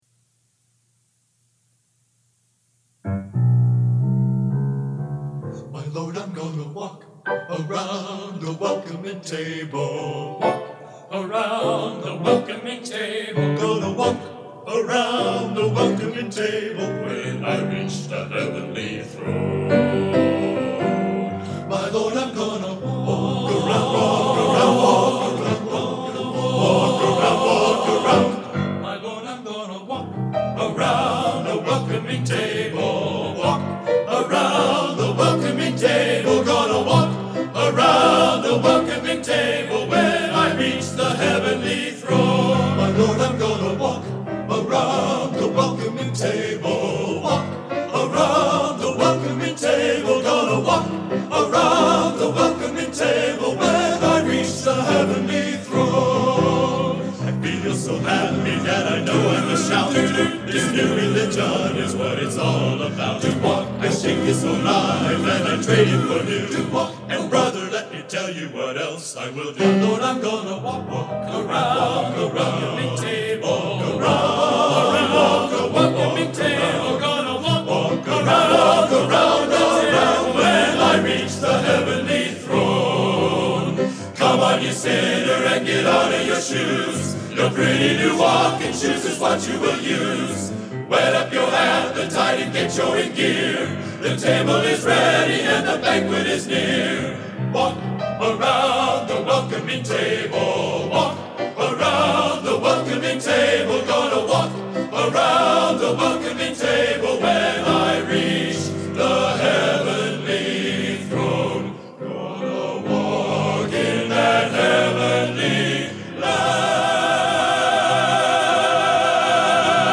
Genre: Gospel | Type: Studio Recording